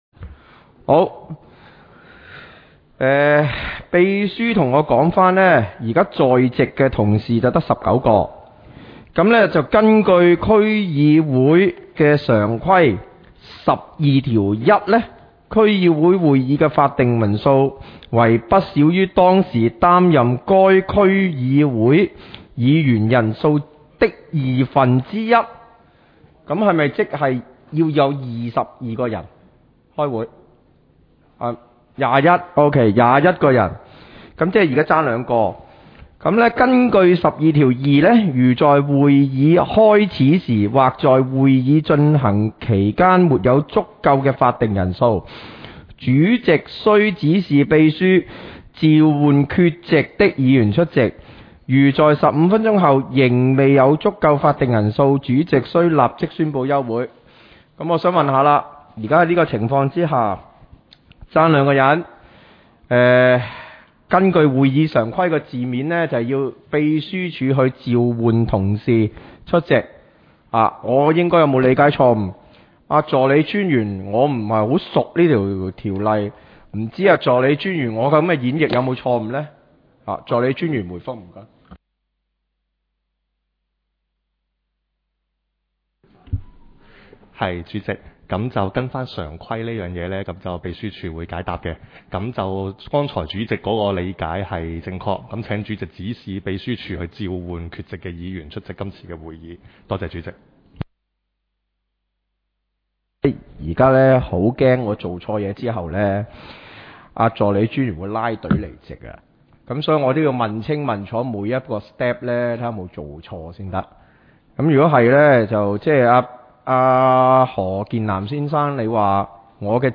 委员会会议的录音记录
交通及运输委员会第一次 (因法定人数不足，会议未能召开)会议 日期: 2021-04-20 (星期二) 时间: 上午10时正 地点: 沙田民政事务处 441 会议室 议程 讨论时间 I 因法定人数不足而休会 00:14:53 全部展开 全部收回 议程:I 因法定人数不足而休会 讨论时间: 00:14:53 前一页 返回页首 如欲参阅以上文件所载档案较大的附件或受版权保护的附件，请向 区议会秘书处 或有关版权持有人（按情况）查询。